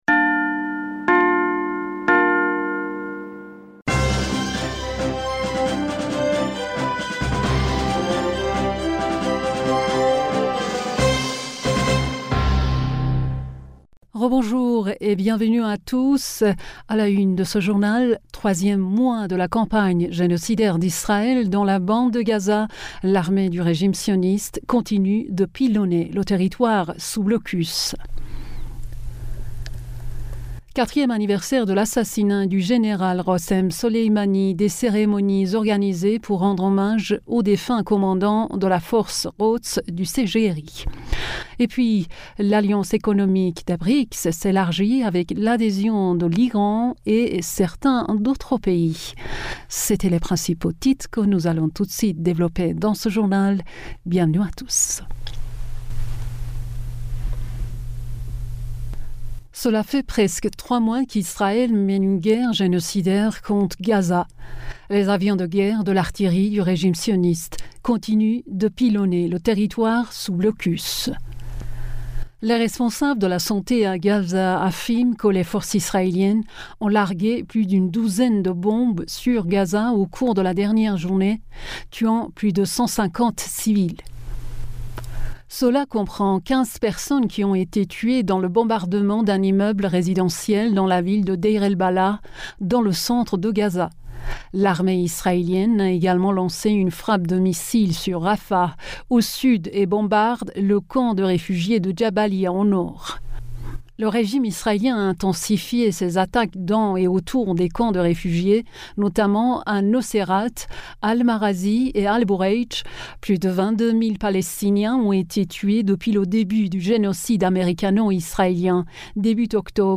Bulletin d'information du 02 Janvier 2024